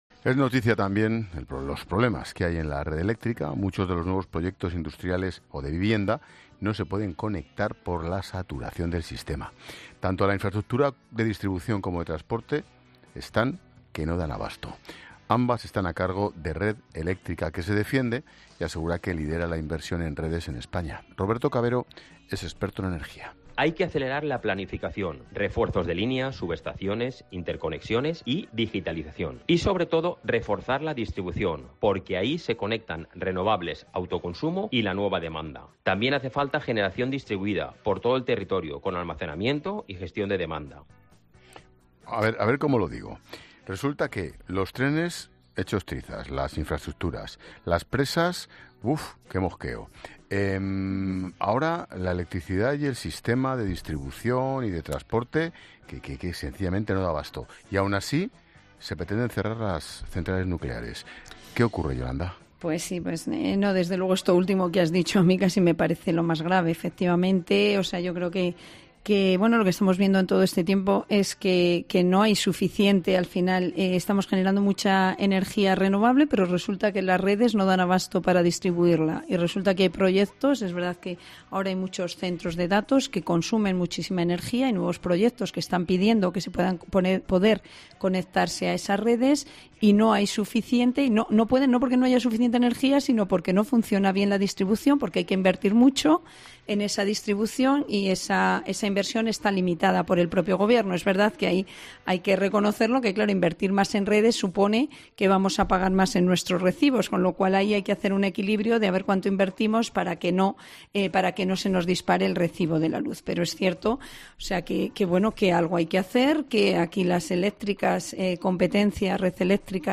Los problemas en la red eléctrica española han alcanzado un punto crítico, tal y como se ha analizado en el programa 'La Linterna' de COPE con Expósito.